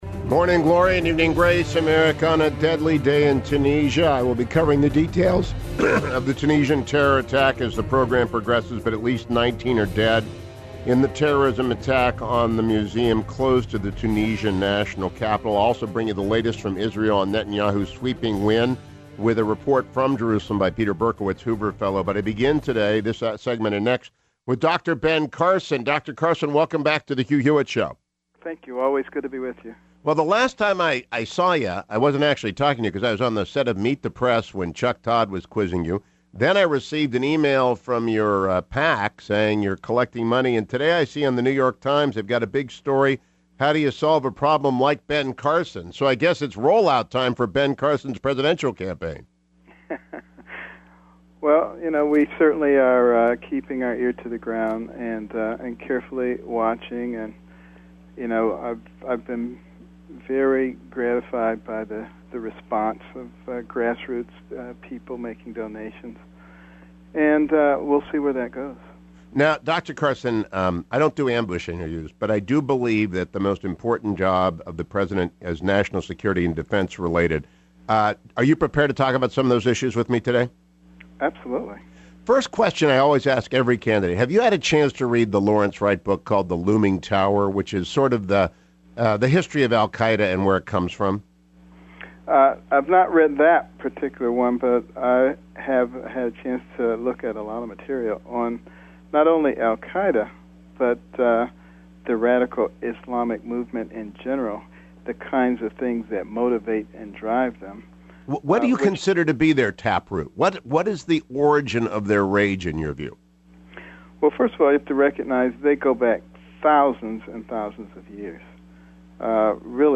Dr. Ben Carson goes in depth on national scurity issues with Hugh Hewitt. Says roots of conflict with Islamic State has roots in dispute between Jacob and Esau; stumbles on NATO status of Baltic States.